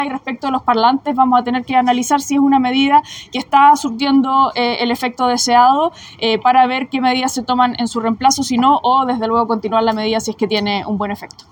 La delegada presidencial, Daniela Dresdner, también fue consultada sobre la efectividad de estos parlantes para evitar delitos, indicando que tampoco tiene cifras respecto de la efectividad, a pesar de que fue el mismo Gobierno el que entregó los más de 25 millones de pesos al municipio para su instalación. Agregó que será necesario revisarlo para evaluar la continuidad de la medida y no descartó su posible reemplazo.